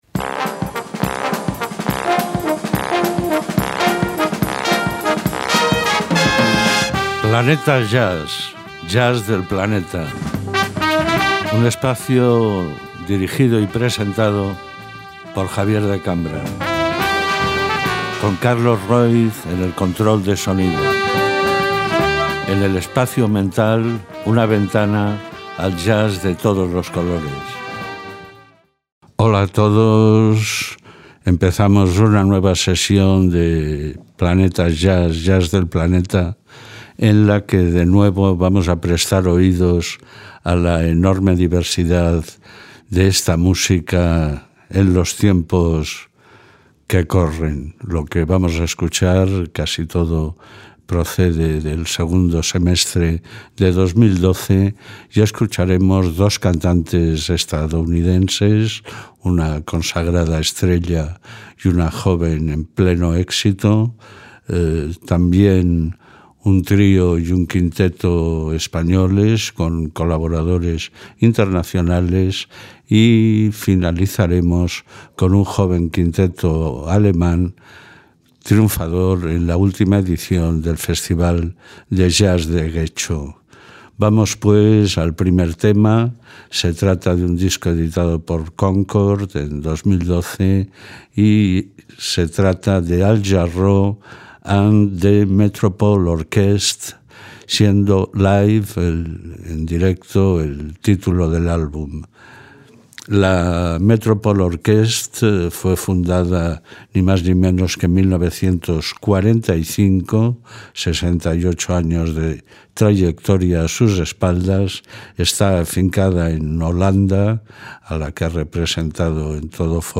Sus 12 programas fueron grabados en los estudios de EEM Radio entre el 22 de noviembre de 2012 y el 28 de febrero de 2013).